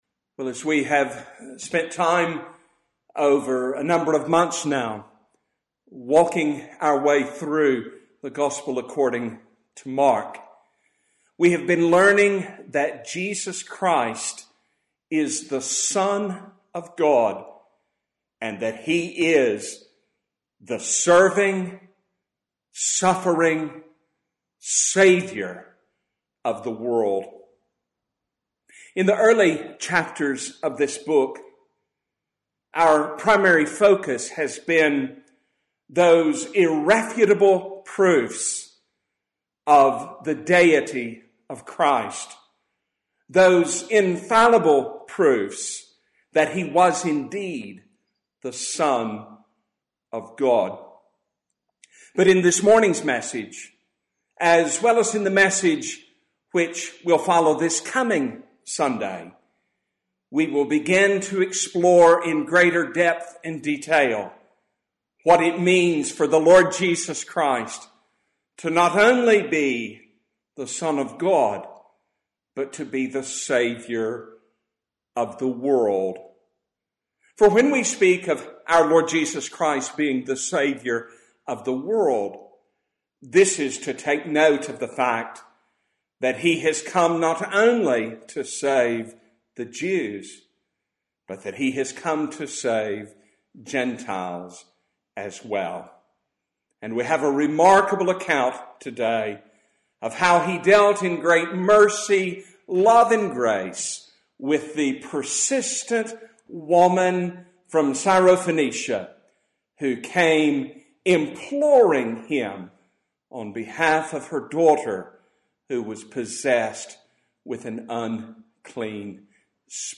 Passage: Mark 7:24-30 Service Type: Sunday Morning